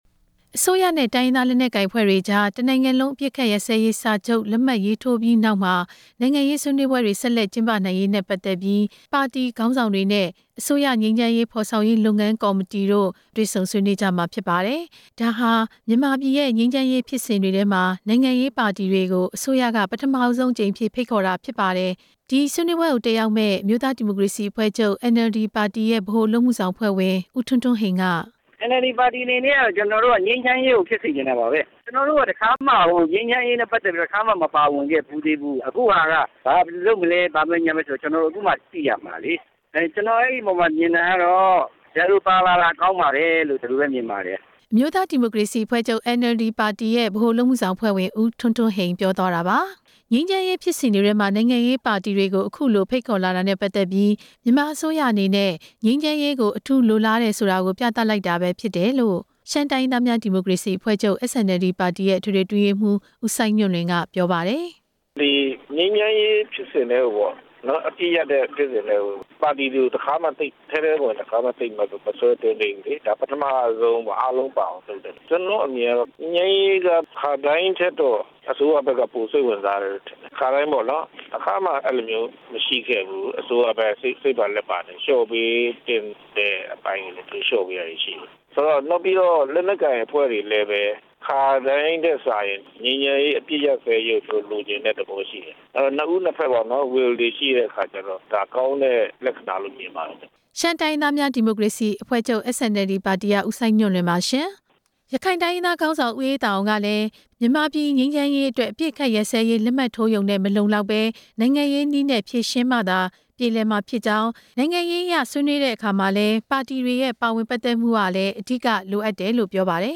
အခုလို မြန်မာပြည်ရဲ့ငြိမ်းချမ်းရေးဖြစ်စဉ်တွေမှာ နိုင်ငံရေးပါတီတွေကို ပထမဆုံးအကြိမ် ဖိတ်ခေါ်လာတာနဲ့ ပတ်သက်ပြီး ပါတီခေါင်းဆောင်တွေရဲ့ အမြင်တွေကို စုစည်းတင်ပြထားတာ နားဆင်နိုင်ပါတယ်။